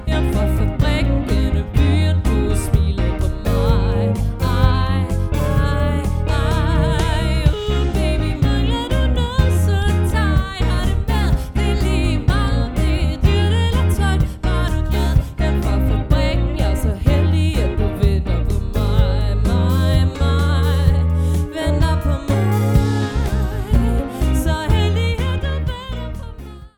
Vokal
Guitar
Keys
Trommer
• Coverband